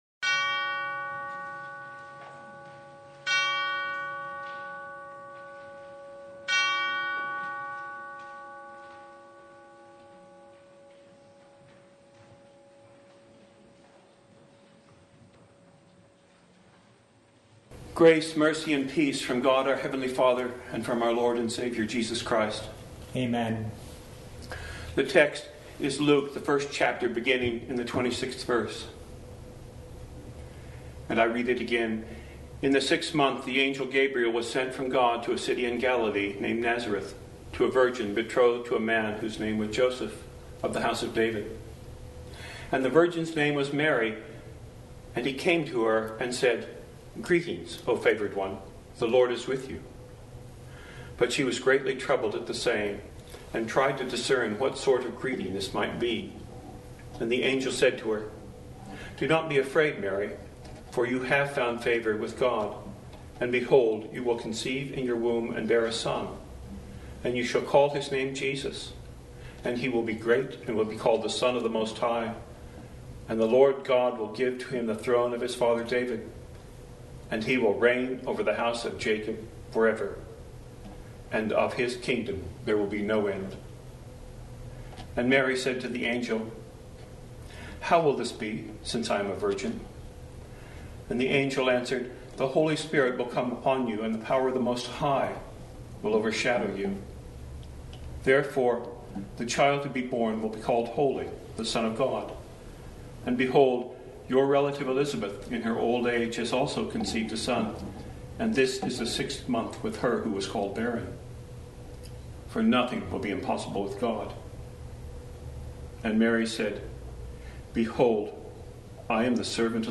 Passage: Luke 1:26-38 Service Type: Lent Midweek Vespers
Sermon Only